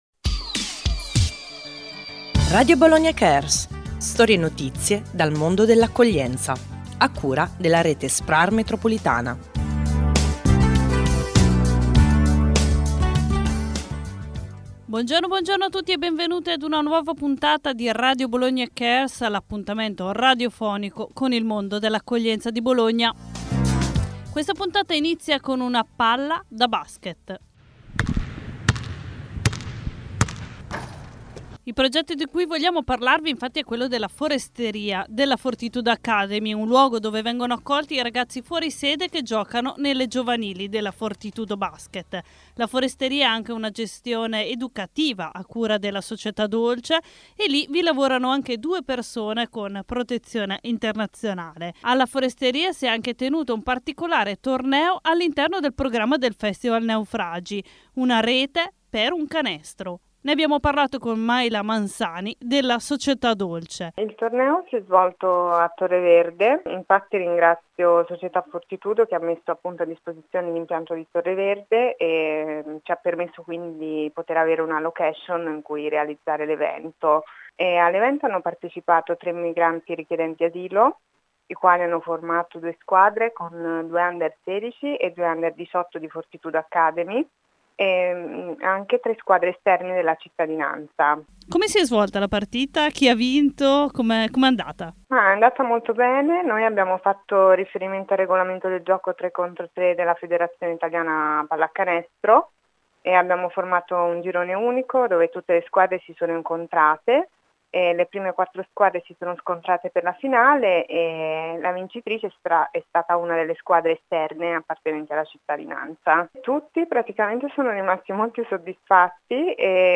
La puntata di Radio Bologna Cares andata in onda mercoledì 5 giugno alle 13 su Radio Città del Capo e in replica domenica 9 aprile alle 14. Dedichiamo questa puntata allo sport.